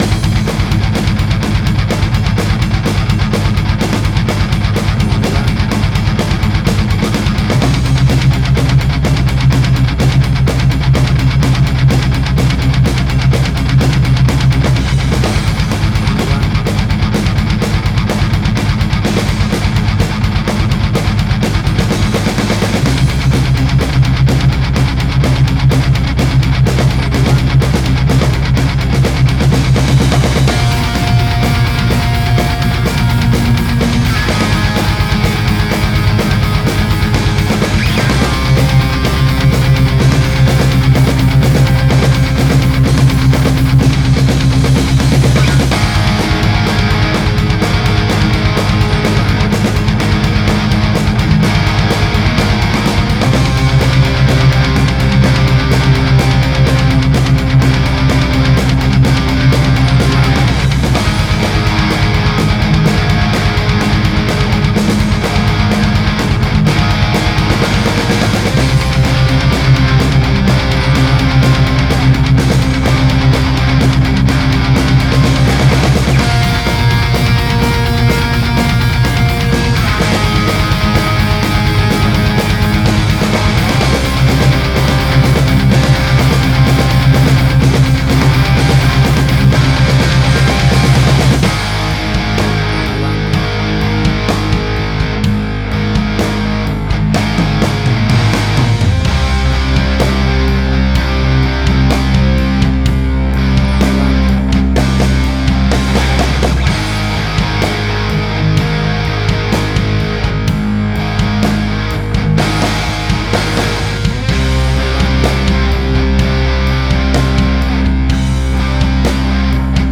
Hard Rock
Heavy Metal.
WAV Sample Rate: 16-Bit stereo, 44.1 kHz
Tempo (BPM): 125